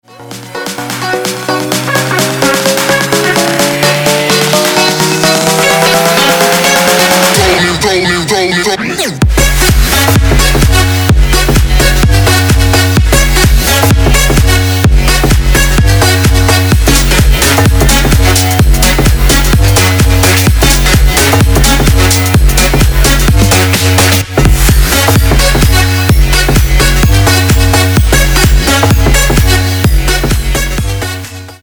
• Качество: 256, Stereo
мужской голос
громкие
future house
Bass